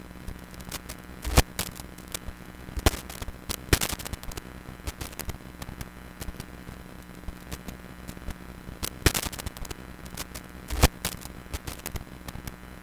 電気音
接触不良音
poor_elec_contact.mp3